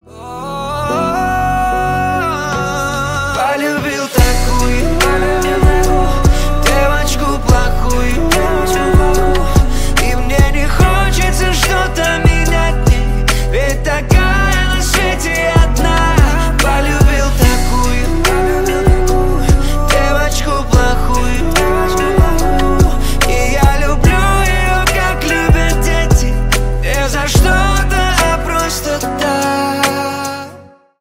Поп Музыка
грустные
спокойные